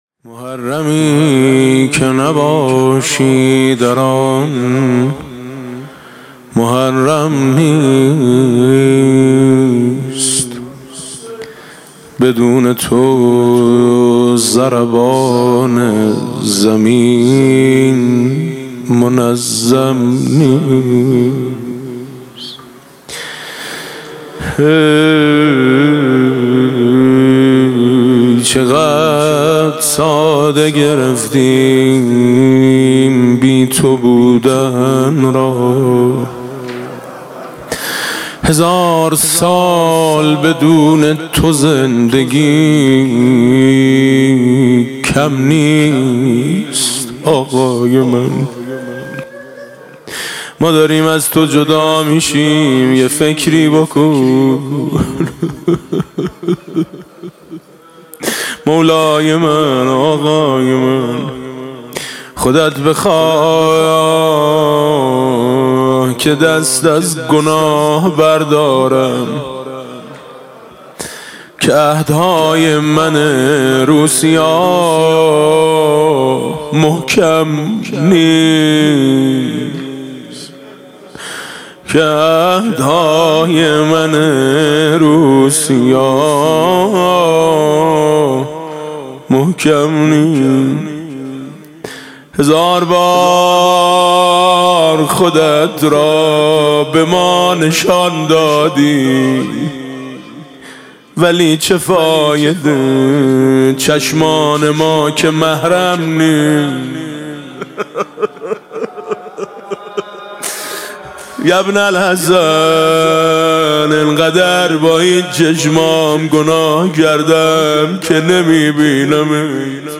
مداحی حاج میثم مطیعی - شب سوم | موسسه فرهنگی هنری اندیشه شهید آوینی